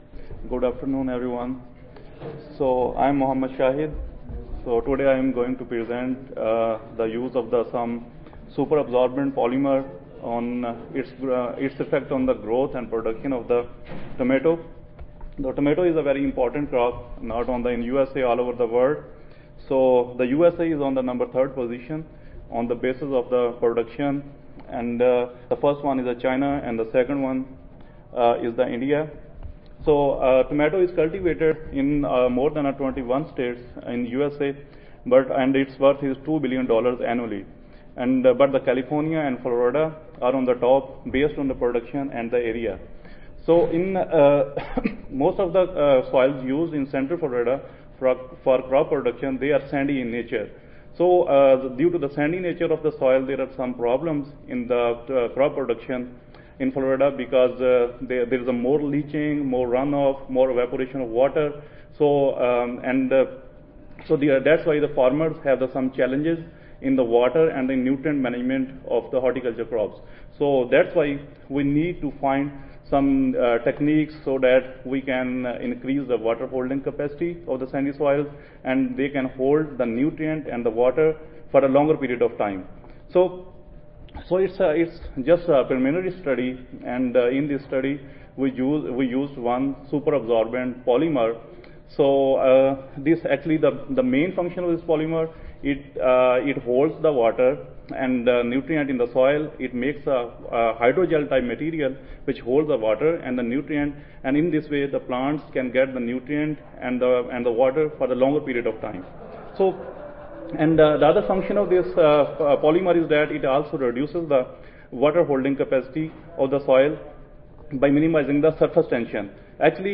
University of Florida Audio File Recorded Presentation